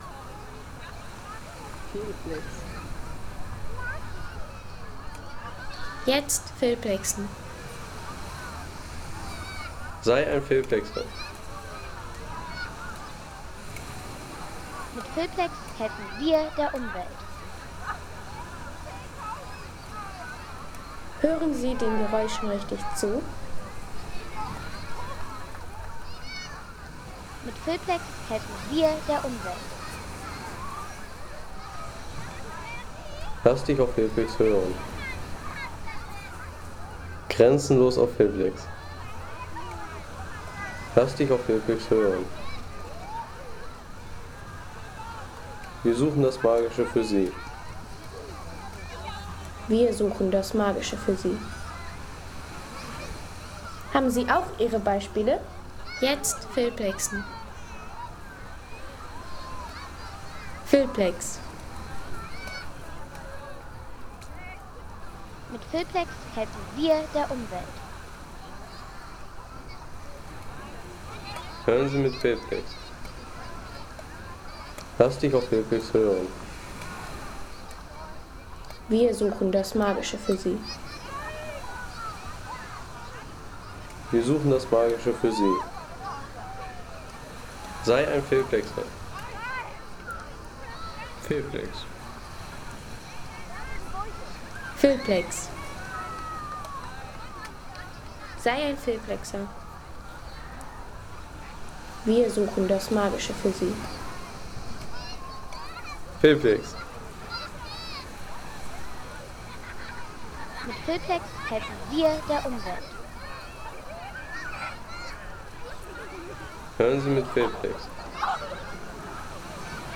Erholungsgenuss für alle, wie z.B. diese Aufnahme: Strand Westerland Sylt
Strand Westerland Sylt